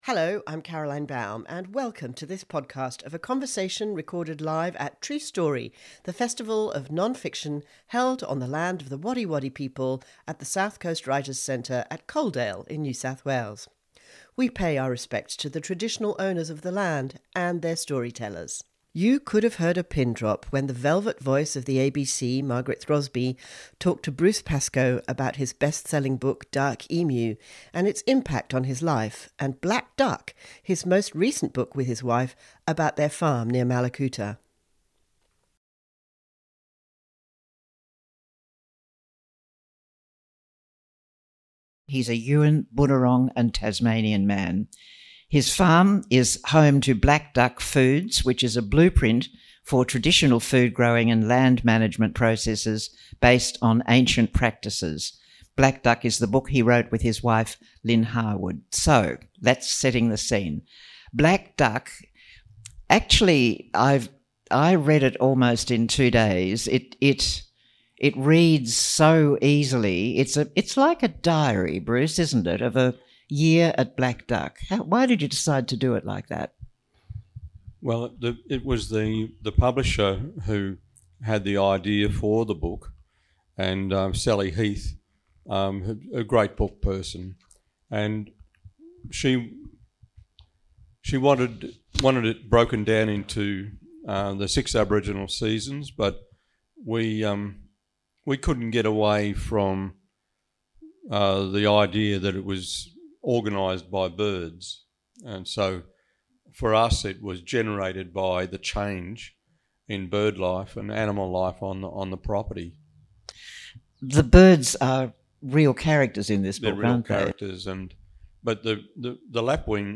Black Duck, a Year at Yumburra is his personal account of his healing on his farm (where he somehow also found time to write a novel, Imperial Harvest). In a candid conversation, he tells Margaret Throsby what it took to rebuild a life, a marriage, and how custodianship of land renewed his sense of purpose.